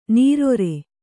♪ nīrore